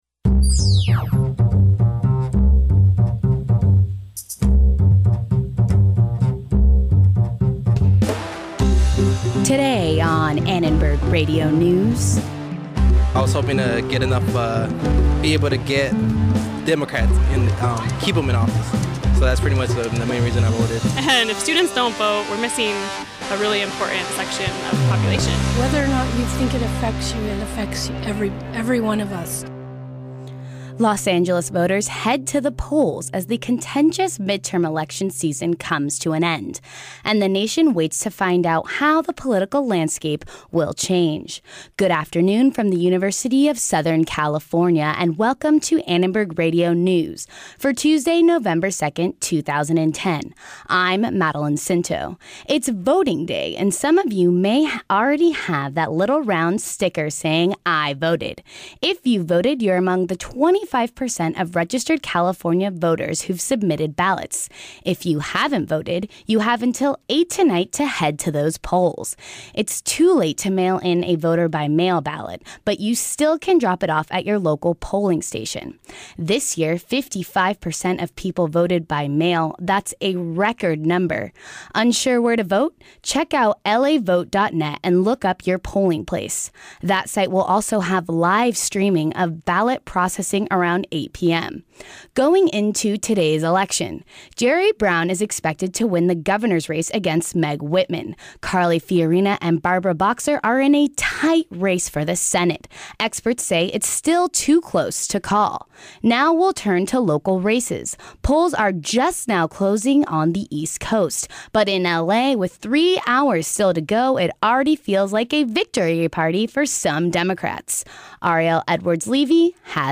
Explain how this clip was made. We also visit the mayor and other democrat supporters at the Vote Labor Rally who already feel they've won the midterm elections. Latino voters are getting robo-phone calls saying voting day is tomorrow.